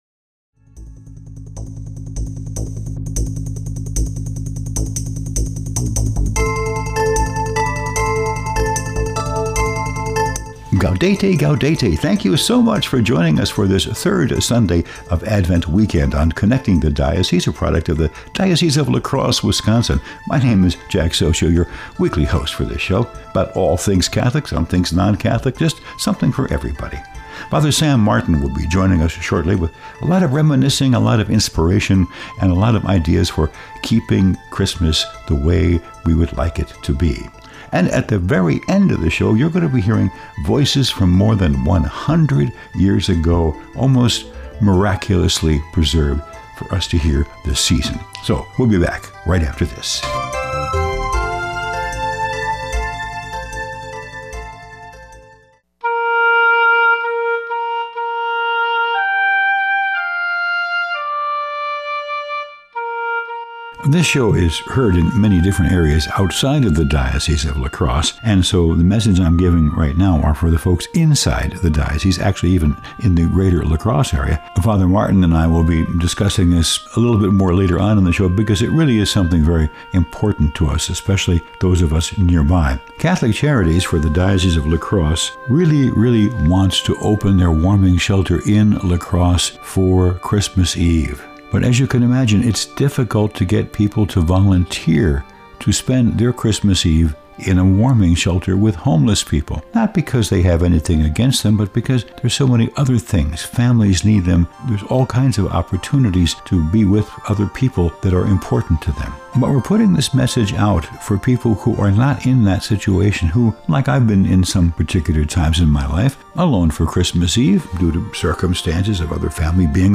April 14th, 2024 Homily